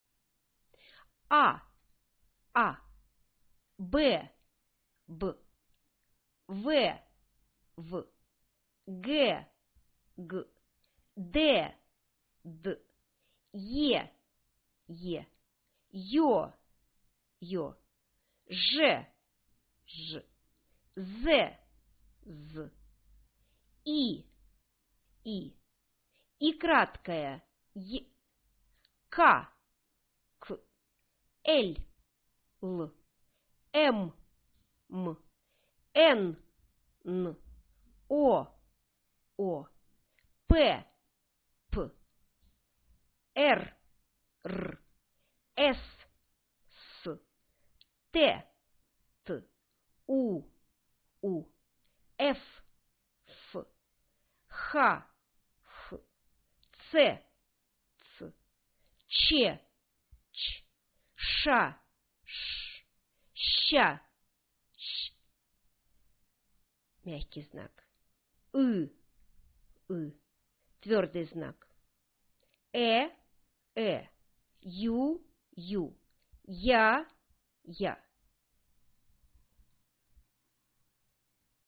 Sound 03 音声ファイル (文字の名前と音). MP3.